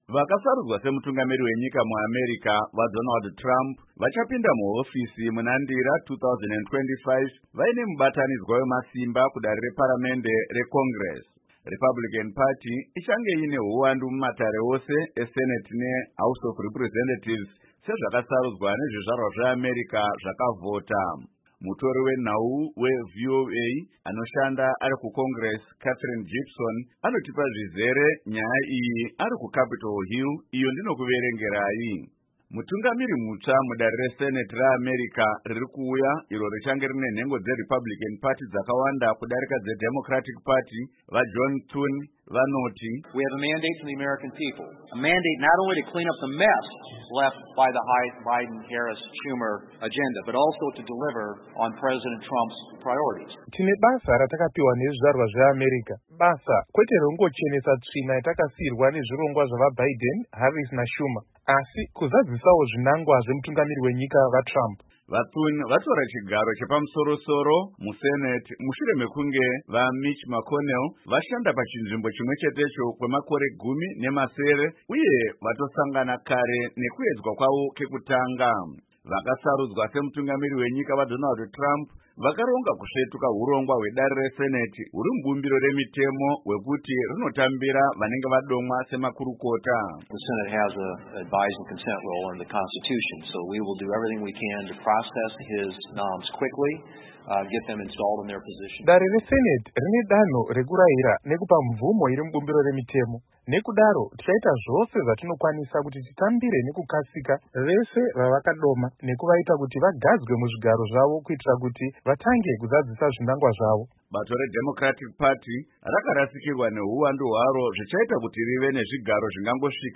Nyaya yandakuverengerai iyi